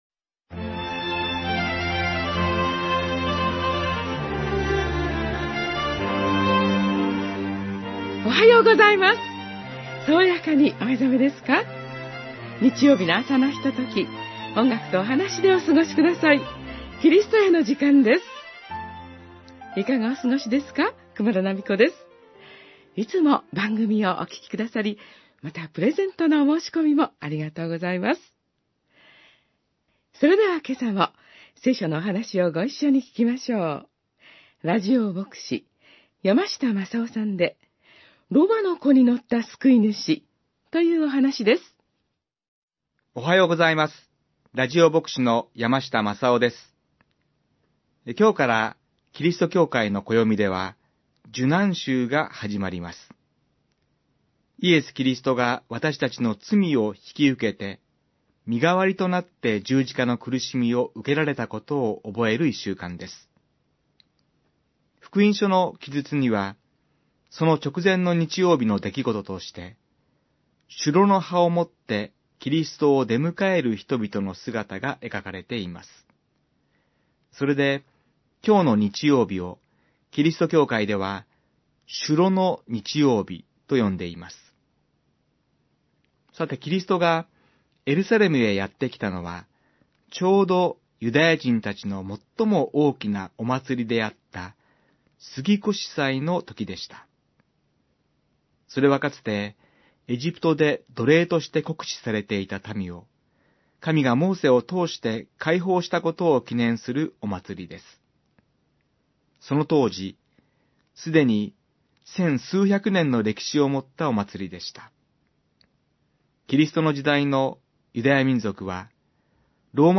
メッセージ： ロバの子に乗った救い主